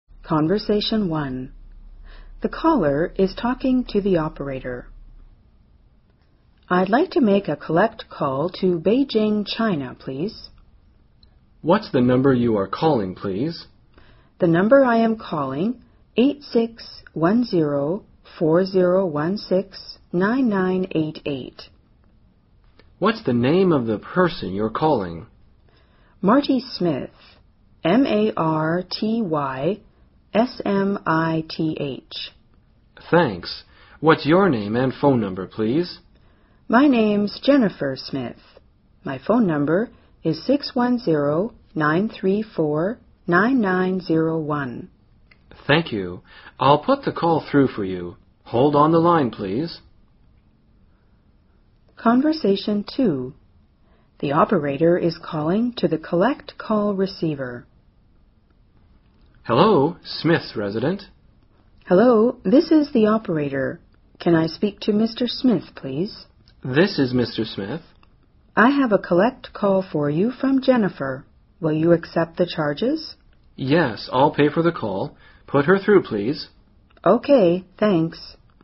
【对话1：打电话的人和接线员通话】
【对话2：接线员打给对方付费电话的接电话对象】